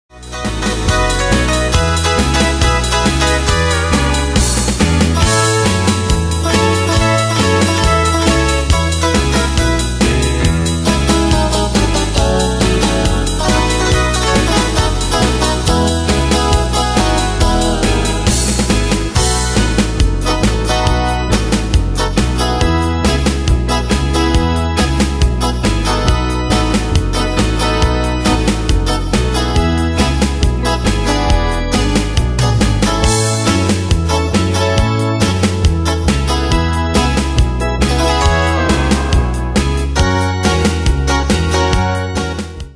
Style: Country Année/Year: 1997 Tempo: 138 Durée/Time: 3.27
Danse/Dance: Twist Cat Id.
Pro Backing Tracks